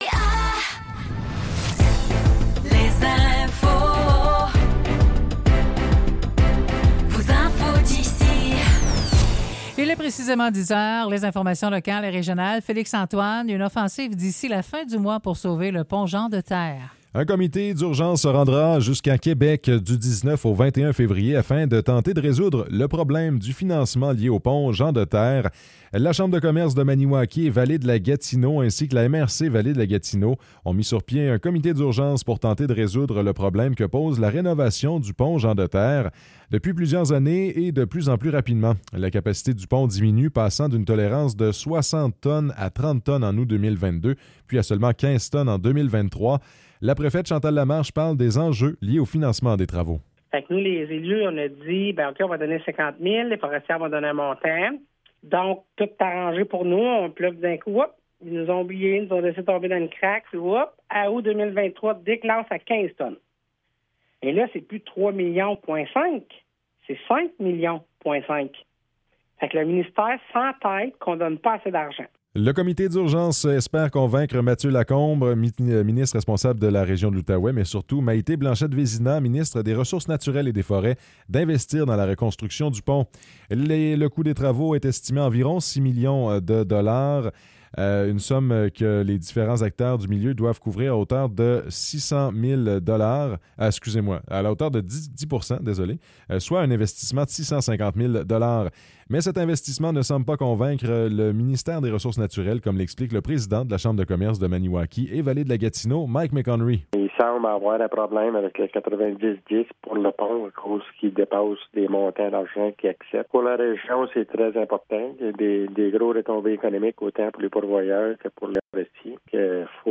Nouvelles locales - 12 février 2024 - 10 h